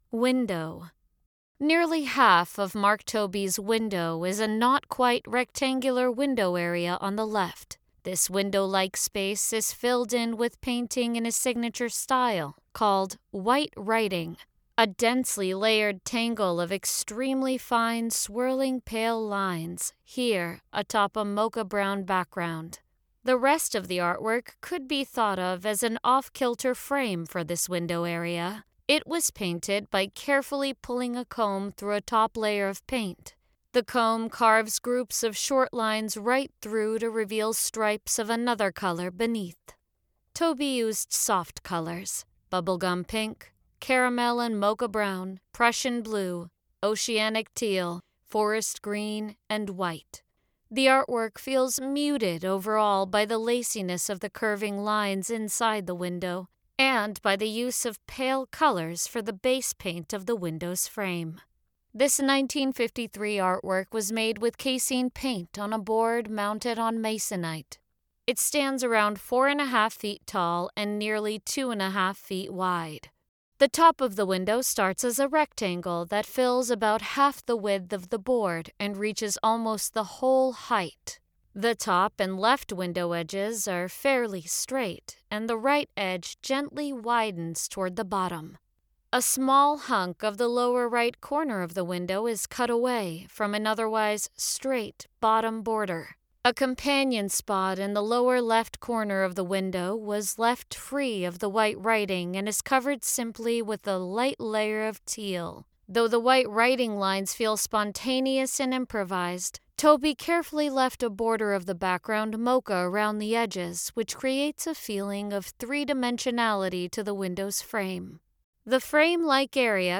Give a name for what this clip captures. Audio Description (03:29)